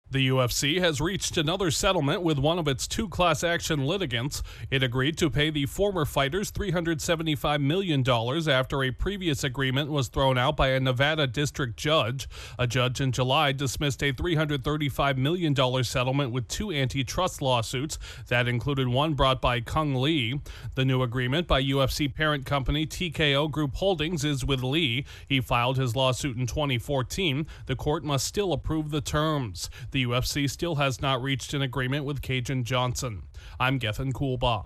The top mixed martial arts promotion in the world is settling a lawsuit with one of its former fighters. Correspondent